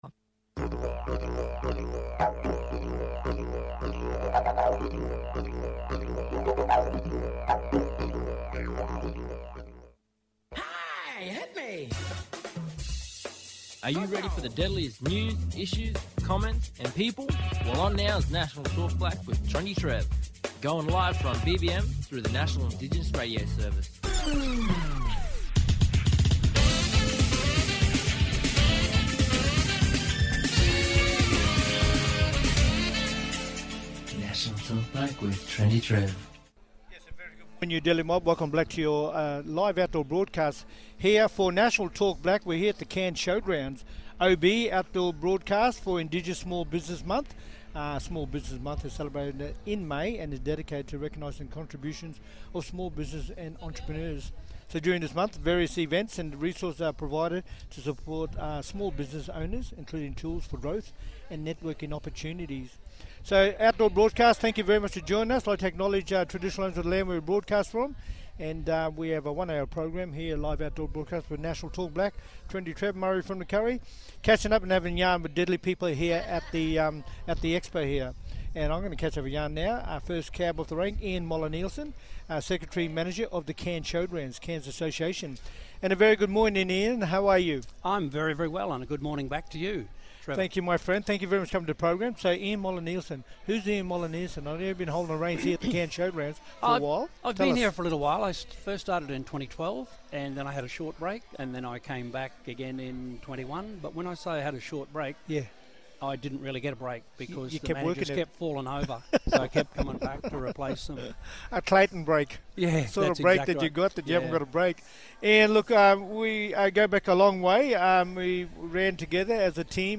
Guests Live from todays event the “2025 Small Business Month”!